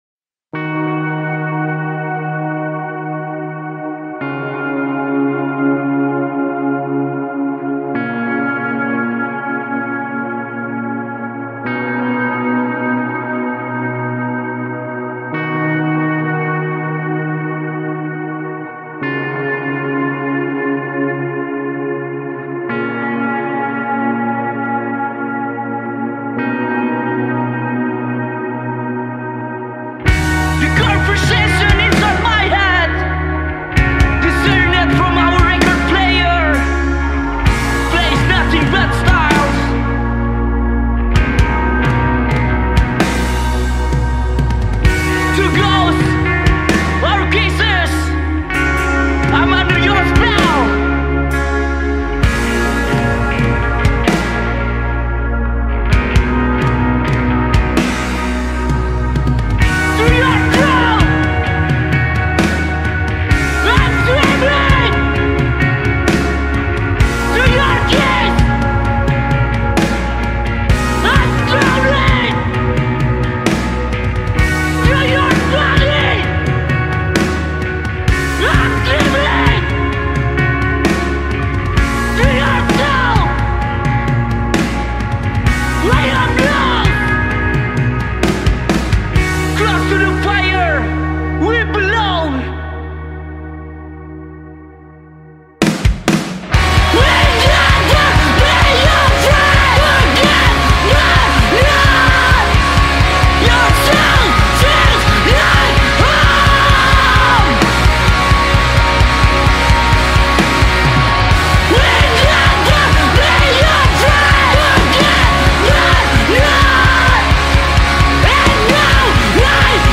- Musik Keras (disediakan admin)